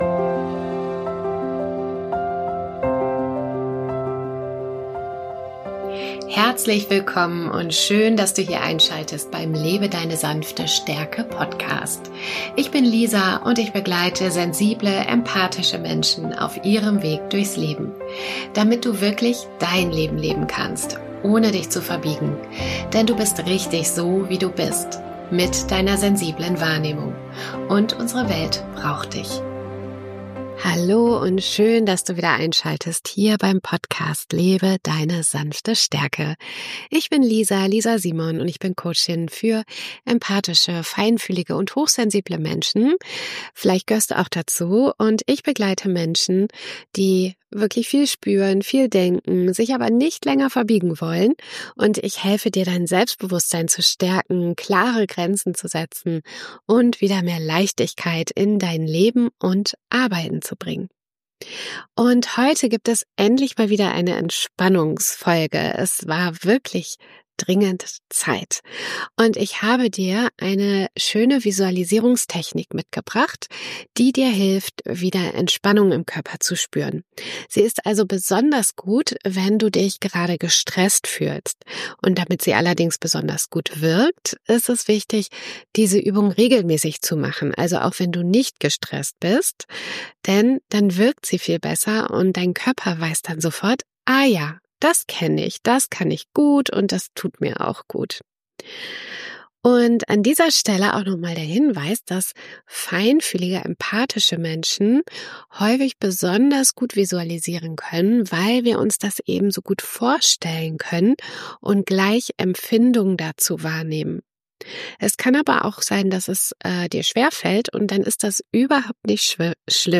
In dieser Folge nehme ich Dich mit auf eine beruhigende, stärkende Visualisierungsreise, die dir hilft, dein Nervensystem zu entspannen und dich wieder ruhiger und leichter zu fühlen.